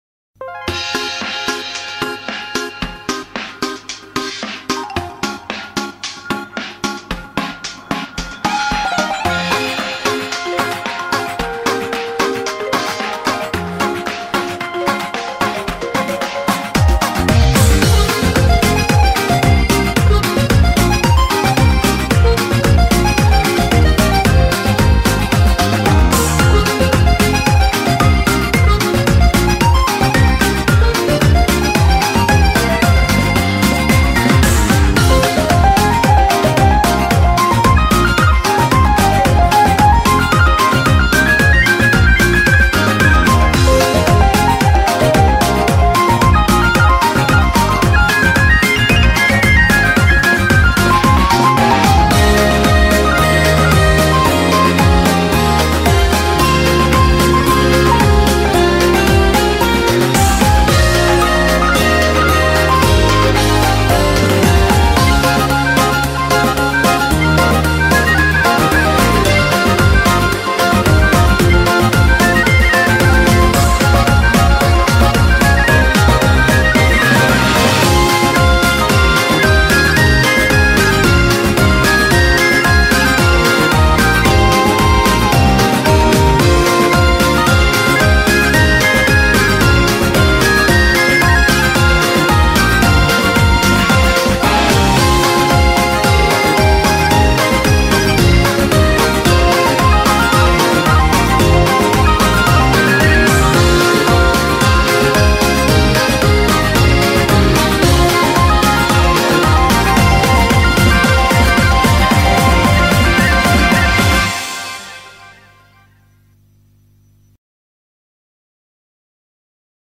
BPM112
Audio QualityPerfect (Low Quality)